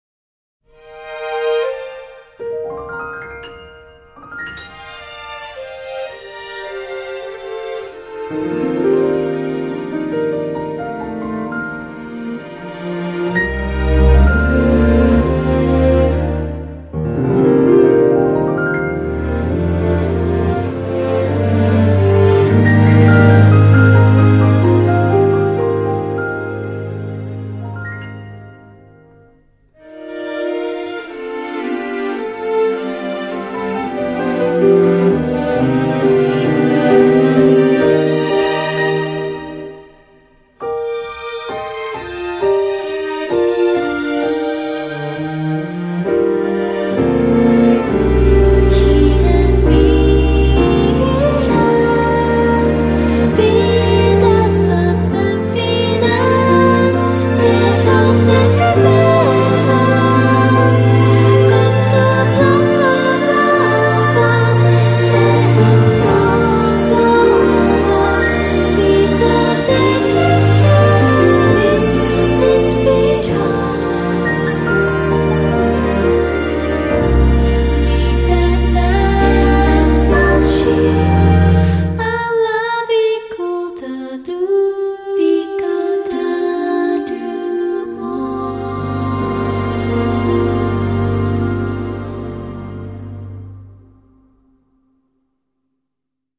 Digital💥Boosted